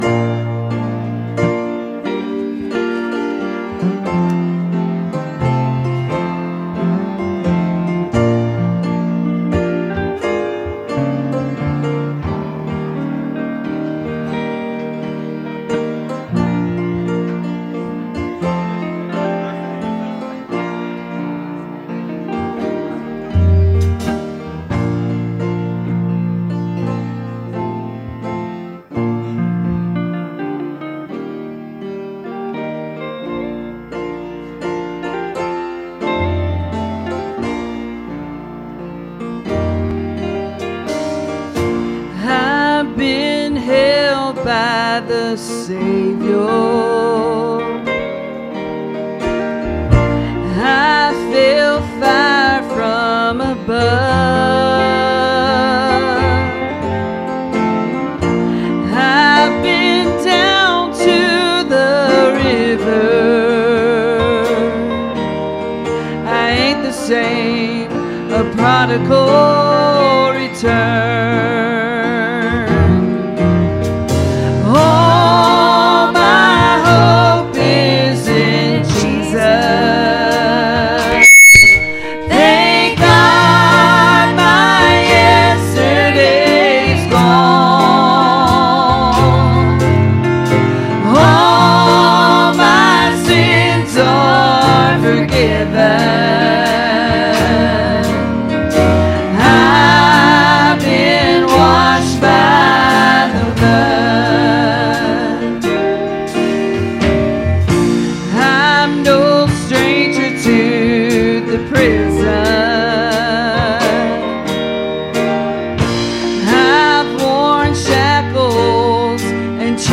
Passage: "Micah 5:2, Luke 2:1-7" Service Type: Sunday Morning Services « Great in Power